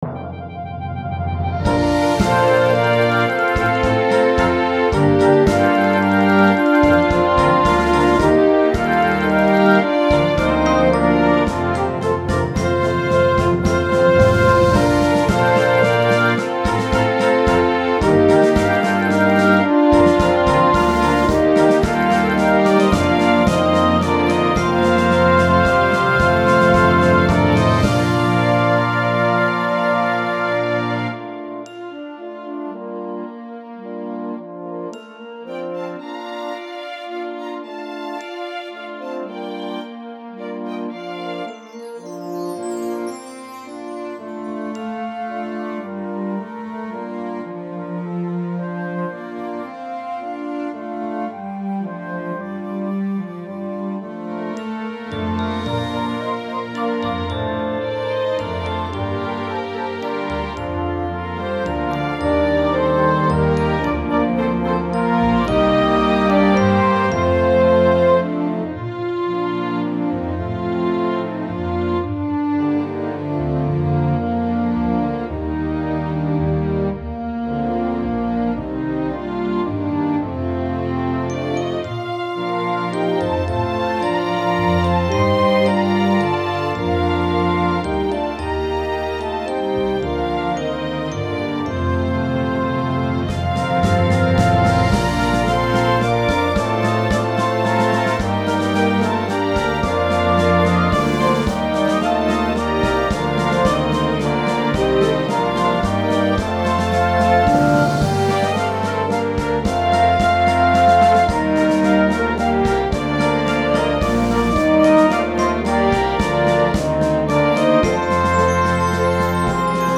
Symphony Orchestra – Intermediate